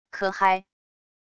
咳咳…wav音频